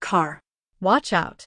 audio_car_watchout.wav